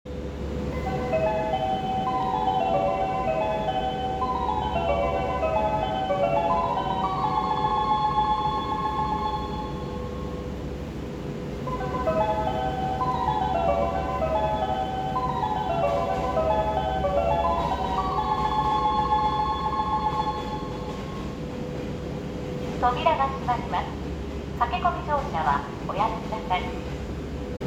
・4000形車載メロディ
常磐線内用（小田急方面）
これを収録したのも馬橋駅です。